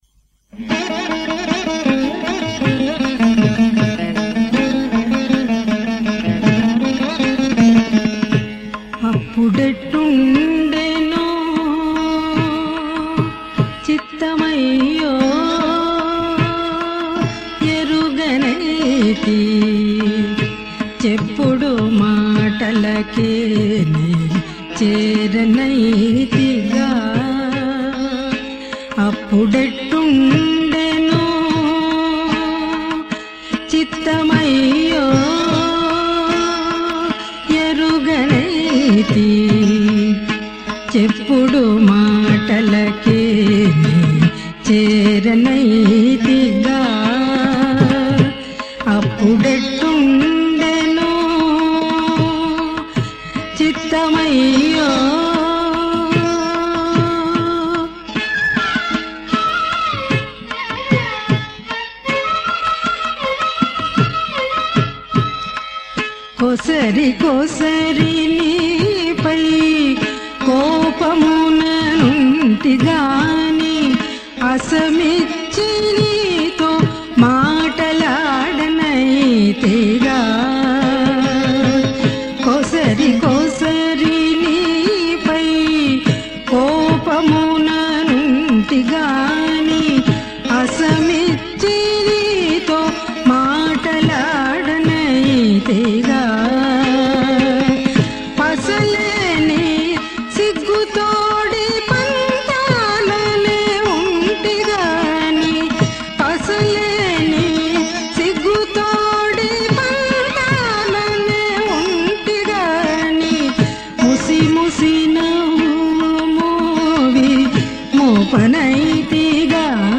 సంకీర్తన